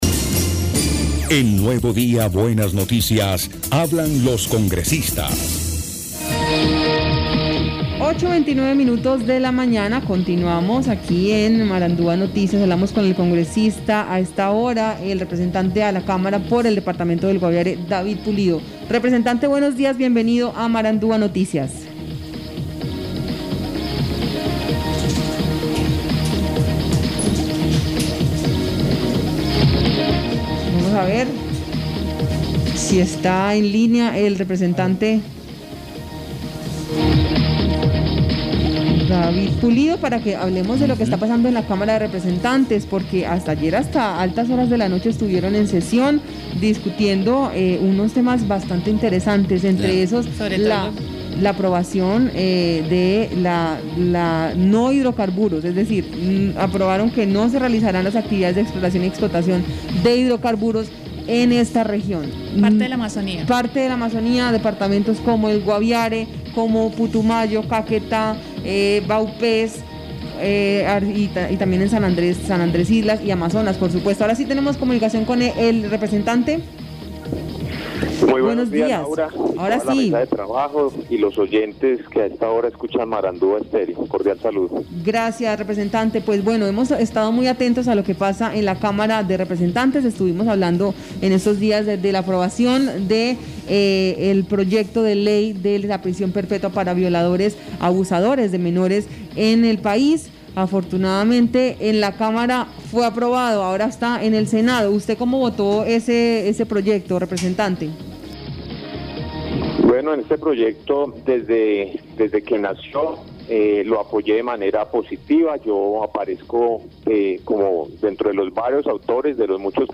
Escuche a David Pulido, representante a la Cámara por el Guaviare.